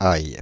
Ääntäminen
Ääntäminen France (Île-de-France): IPA: /aj/ Paris: IPA: [aj] Haettu sana löytyi näillä lähdekielillä: ranska Käännös Konteksti Ääninäyte Substantiivit 1. garlic ruoanlaitto, kasvitiede UK Suku: m .